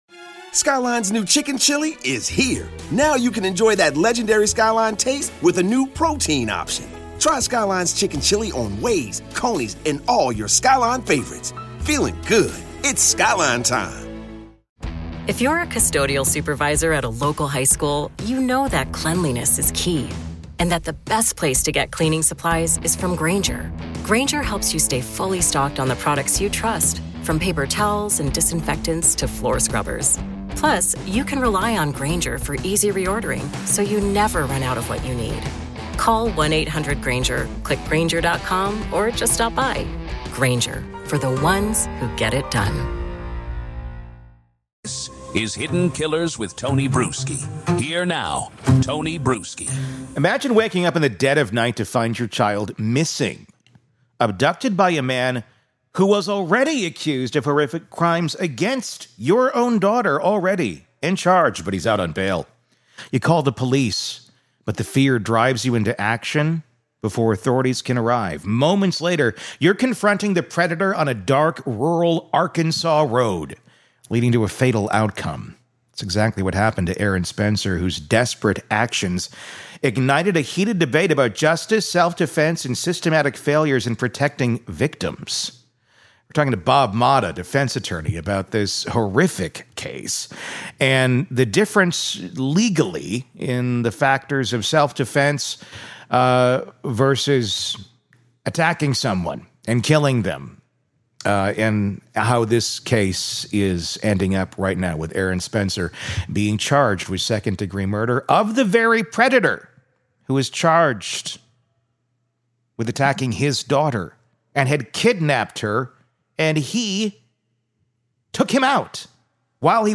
In this conversation, we explore how this case has exposed systemic failures—from weak bail policies to baffling prosecutorial decisions. We look at Arkansas’s stand-your-ground and defense-of-others laws, and why they should apply.